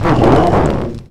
Cri de Grotichon dans Pokémon X et Y.